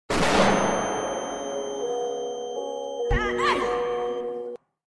Flash Bang
flash-bang.mp3